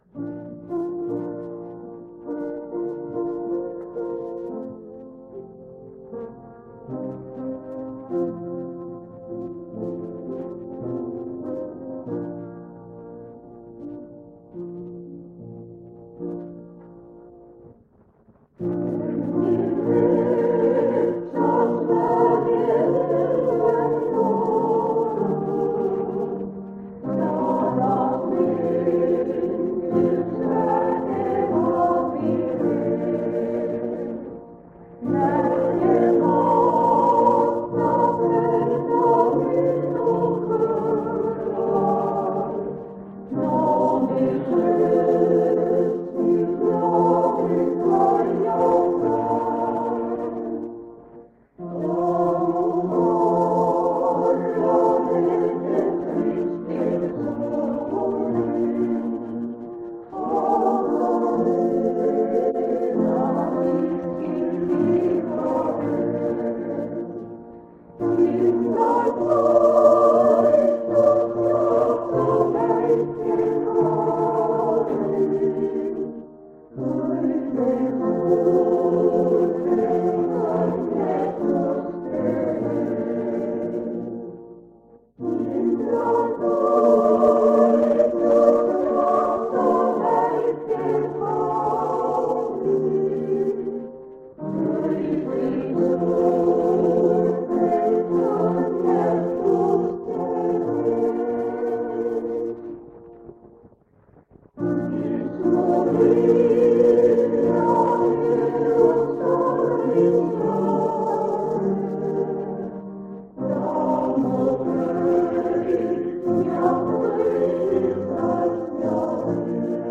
Kingissepa adventkogudusel on külas Pärnu adventkogudus.
Kõlab ka kuus muusika-ettekannet. Lindistus vanalt lintmaki lindilt.